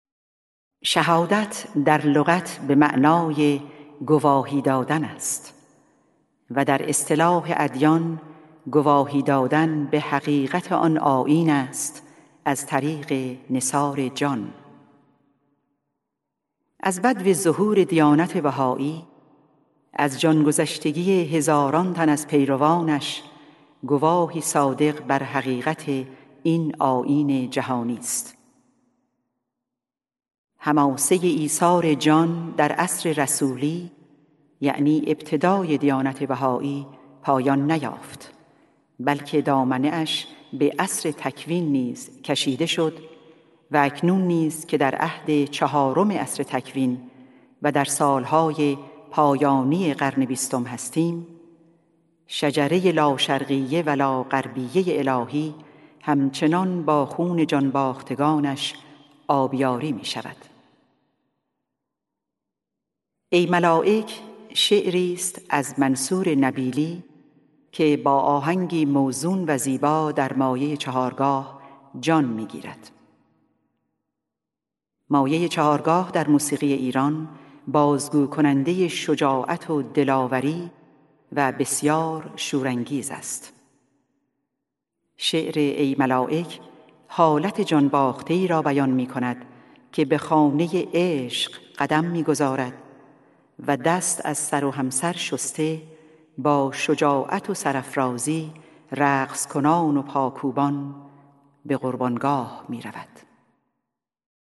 سرود - شماره 3 | تعالیم و عقاید آئین بهائی
«ای ملائک» شعری است از منصور نبیلی که با آهنگی موزون و زیبا در مایه ی چهارگاه جان می گیرد. مایه ی چهارگاه در موسیقی ایران بازگو کننده ی شجاعت و دلاوری و بسیار شورانگیز است.